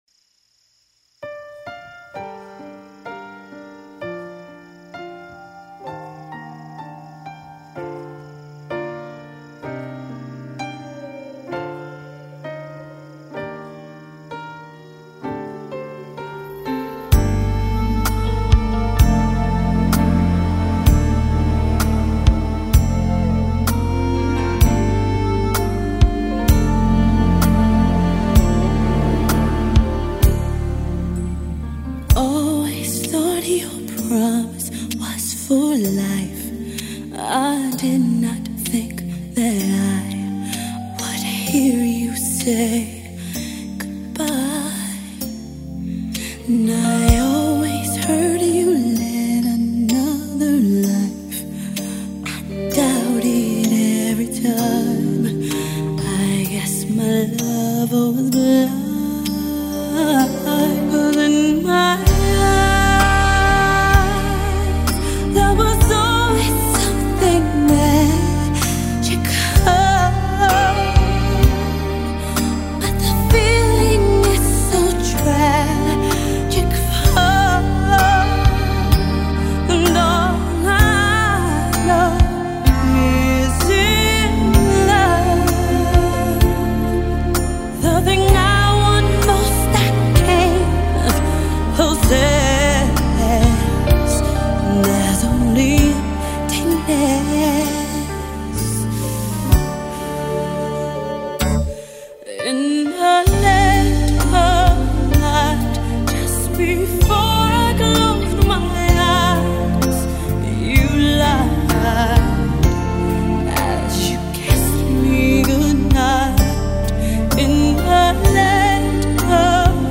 她的歌声婉转动听，轻柔处更如小鸟依人。